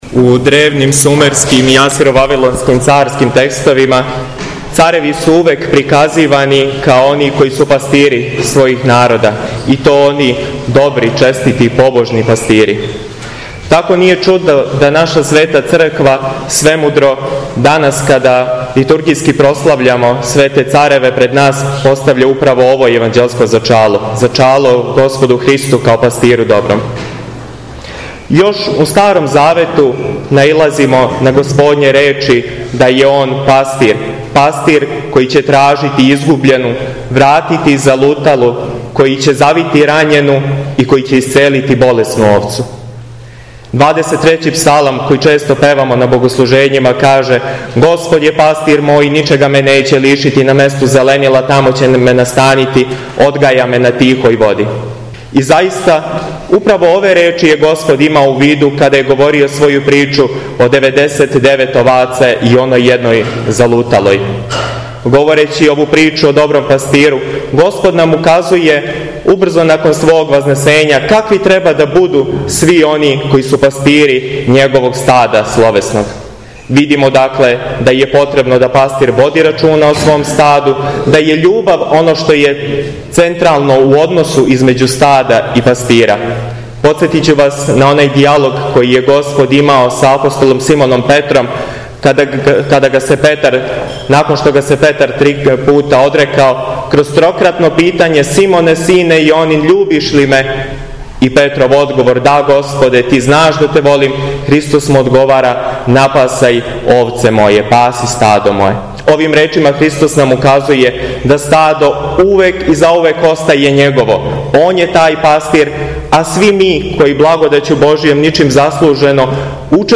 Слушамо ту беседу